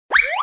Звуки поскальзывания